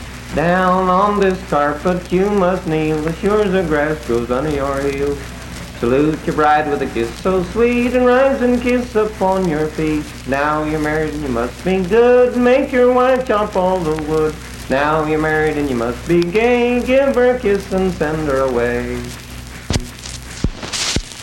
Unaccompanied vocal performance
Verse-refrain 1(8).
Dance, Game, and Party Songs
Voice (sung)